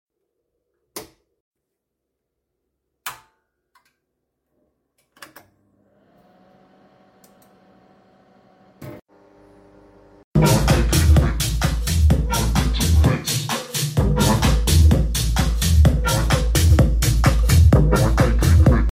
PA Check JBL + Tbox Sound Effects Free Download